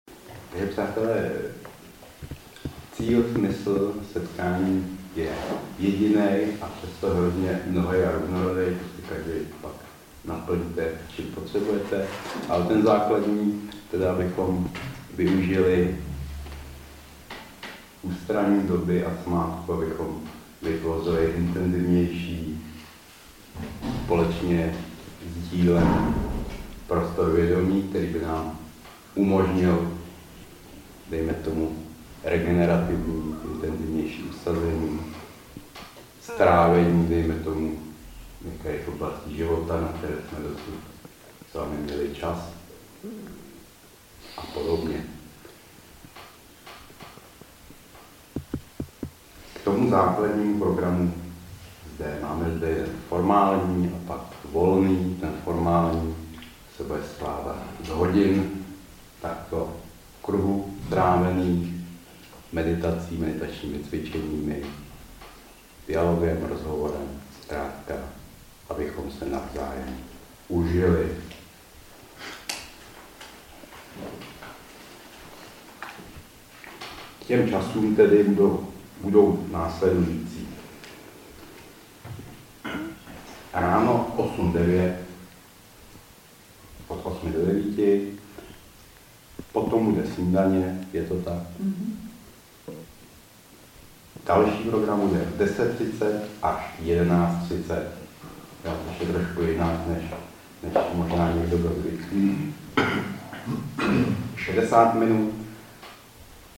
Jóga vědomí slovem 7 - Audiokniha obsahuje nahrávky ze setkání jógy vědomí. Padmafarma, květen 2017.